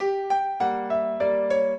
piano
minuet10-7.wav